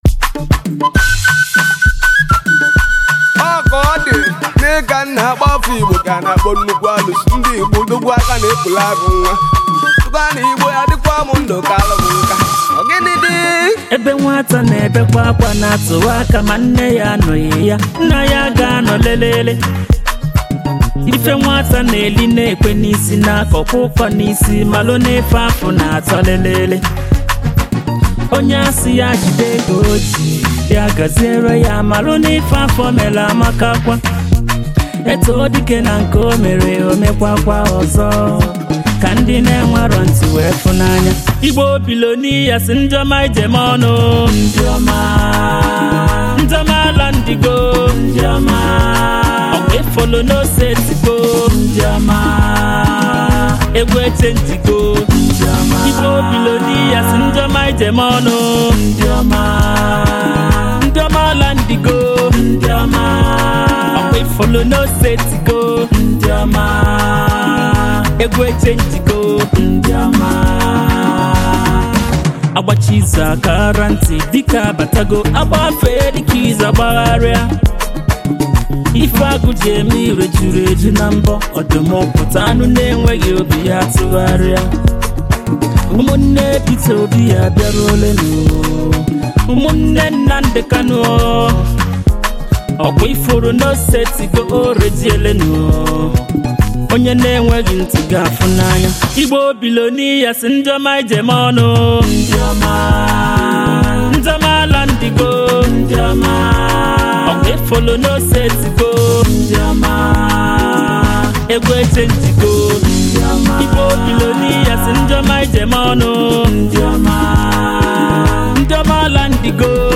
His melody and harmony is straight to the soul.